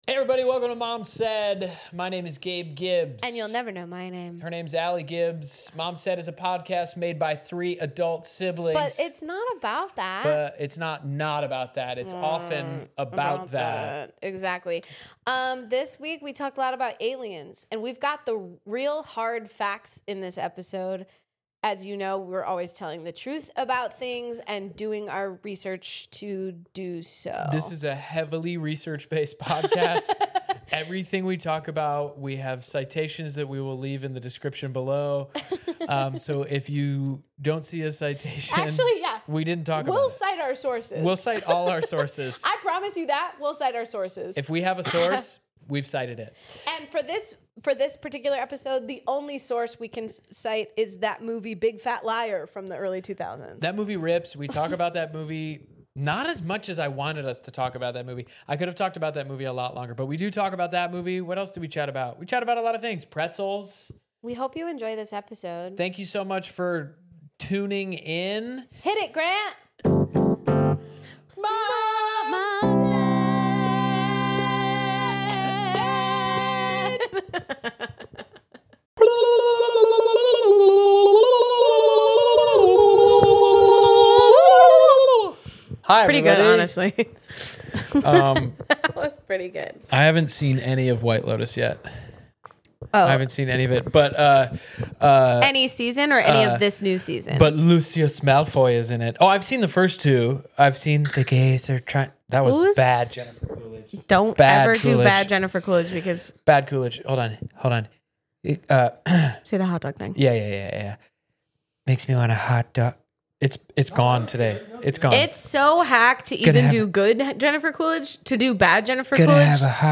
Listen, what would YOU ask Siri if you knew she had to answer honestly? In this episode the siblings talk about wanting answers to extraterrestrial beings, Cardi B’s phone number, and Remi Wolf’s voice notes.